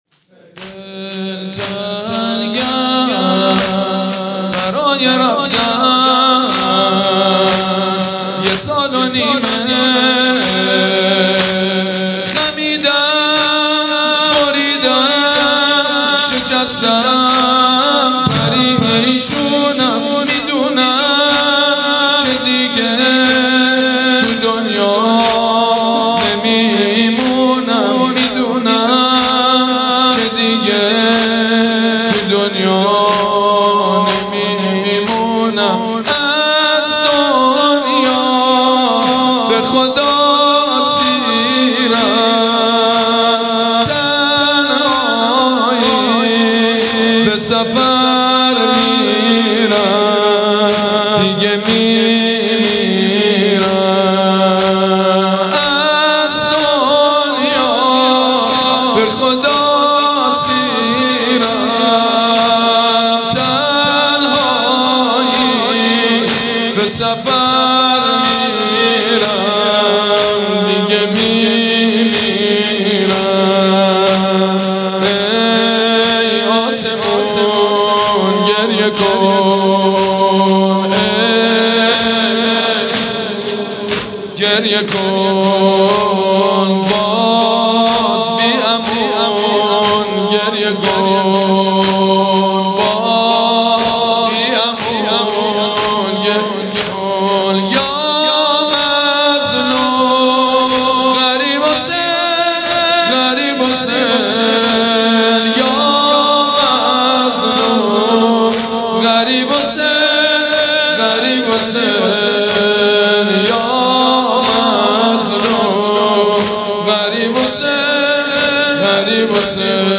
مداحی حاج سید مجید بنی فاطمه بمناسبت وفات حضرت زینب کبری سلام الله علیها